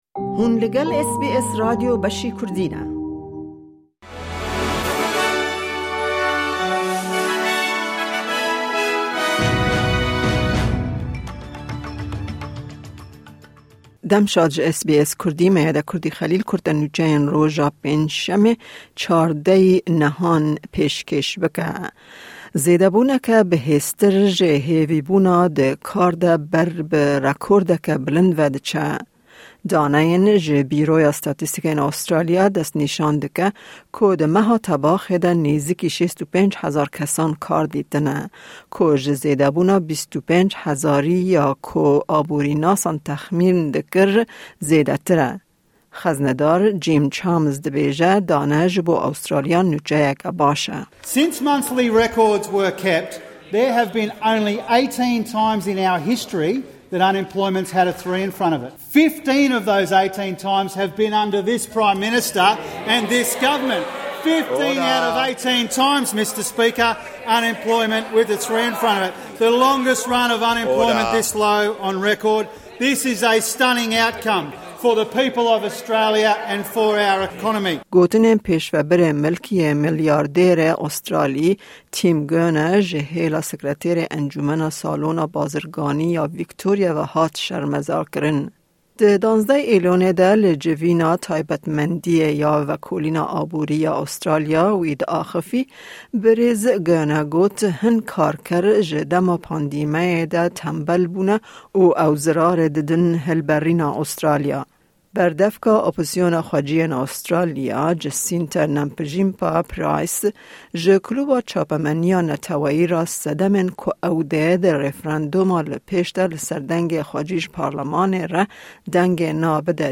Kurte Nûçeyên roja Pêncşemê 14î Îlona 2023